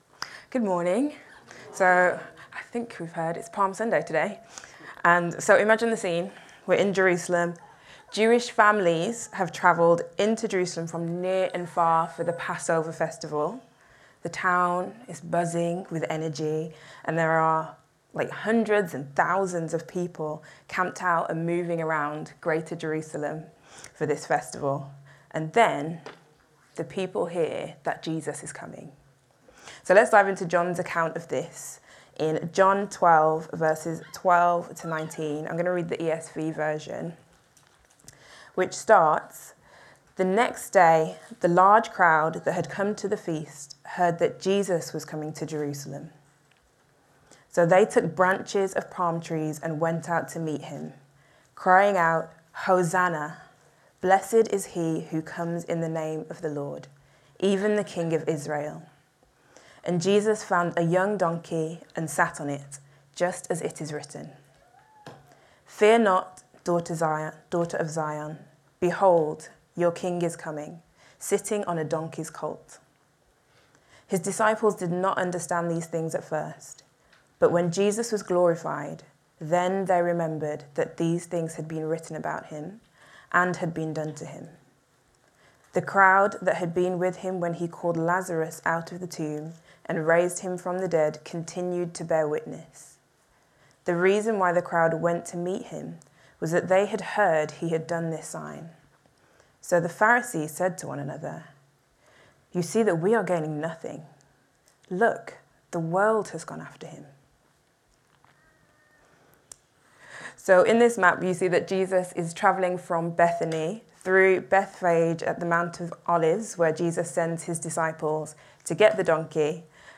Download Palm Sunday | Sermons at Trinity Church